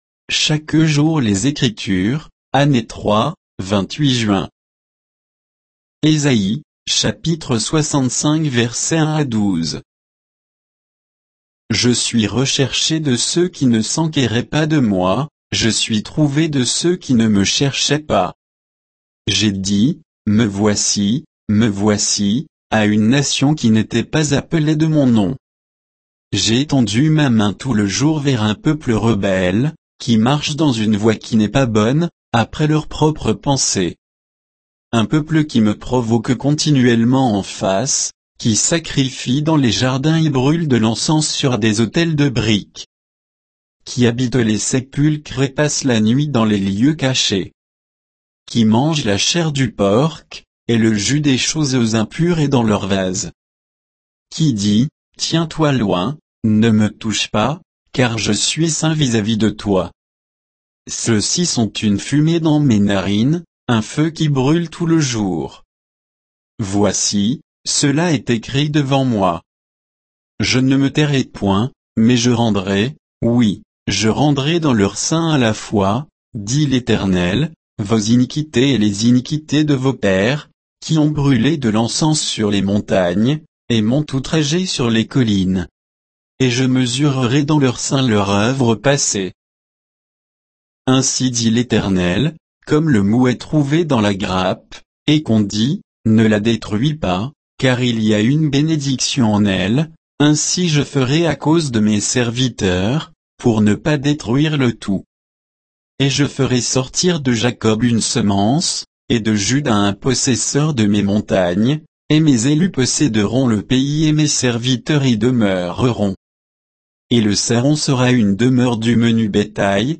Méditation quoditienne de Chaque jour les Écritures sur Ésaïe 65